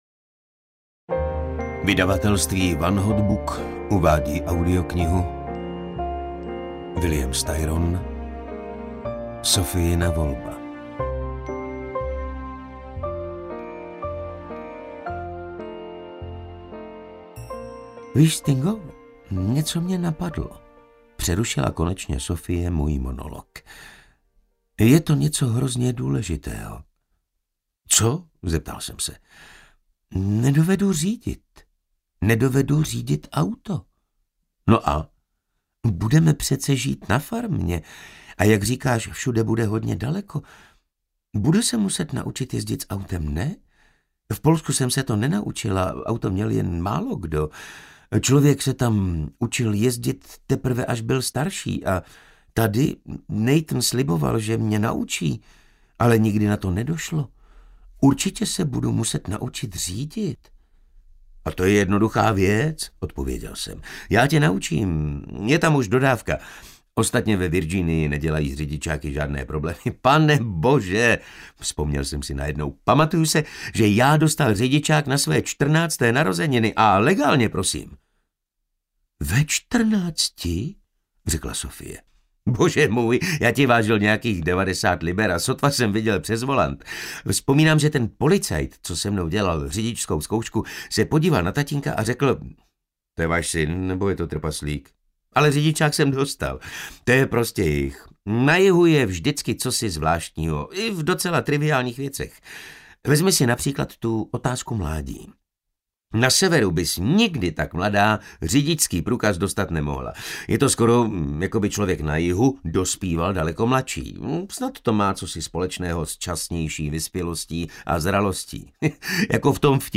Sophiina volba audiokniha
Ukázka z knihy